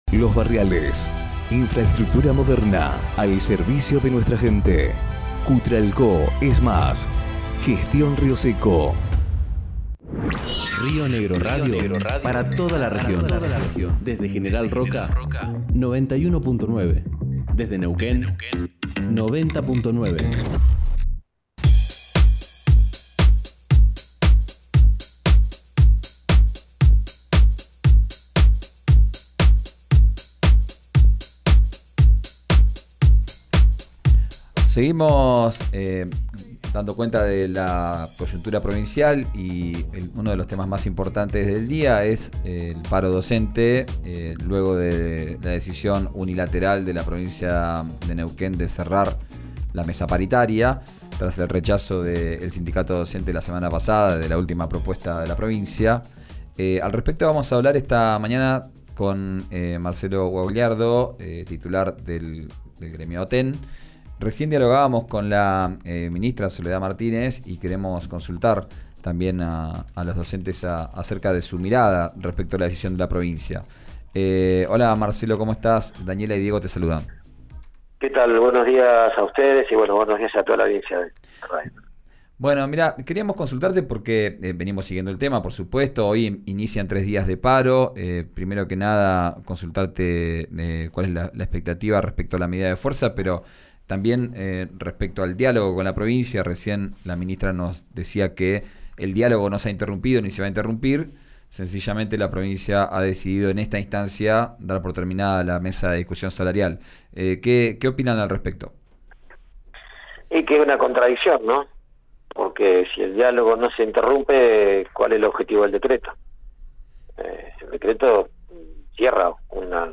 Escuchá a la ministra de Educación Soledad Martínez en RÍO NEGRO RADIO: